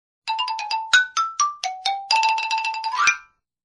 알림음 8_장난기가득한멜로디.mp3